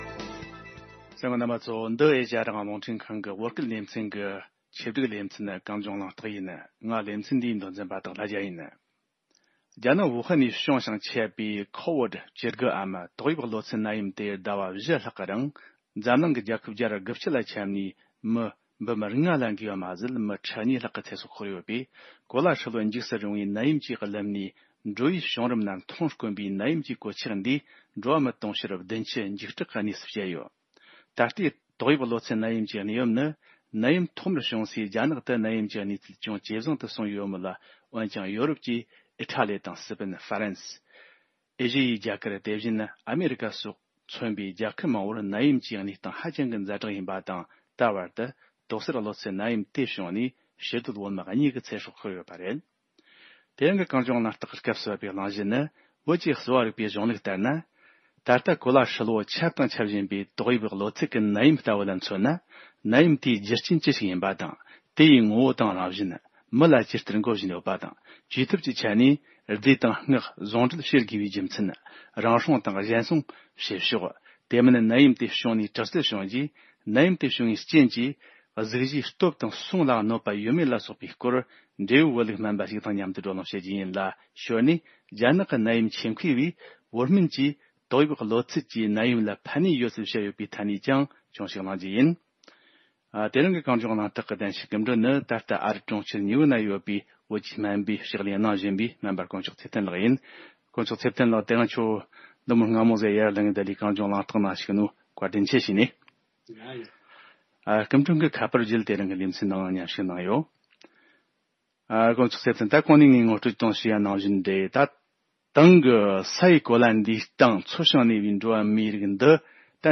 འབྲེལ་ཡོད་བོད་ལུགས་སྨན་པ་ཞིག་དང་མཉམ་དུ་བགྲོ་གླེང་བྱེད་རྒྱུ་ཡིན་ལ།